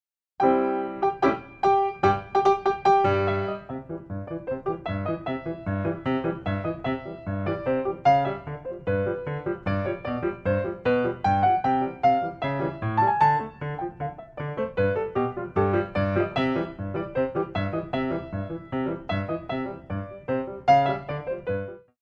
Piqué
Medley
Traditional